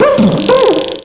pokeemerald / sound / direct_sound_samples / cries / trubbish.aif
trubbish.aif